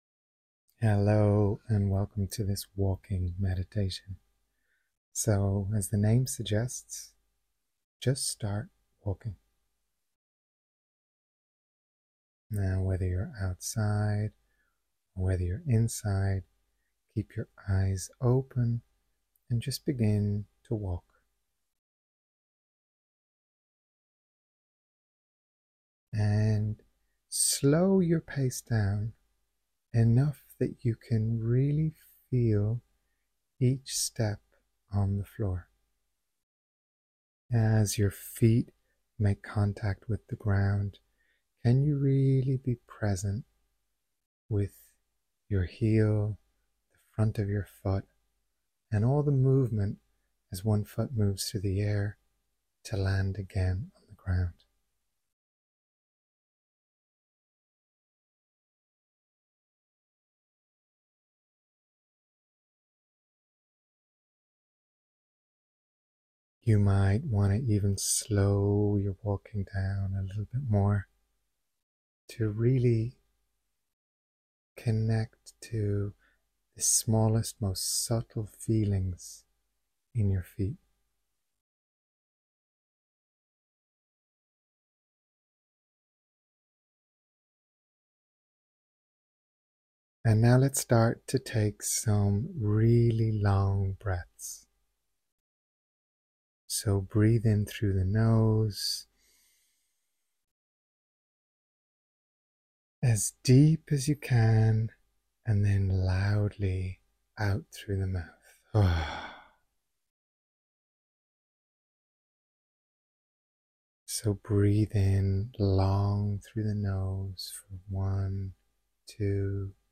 About this Meditation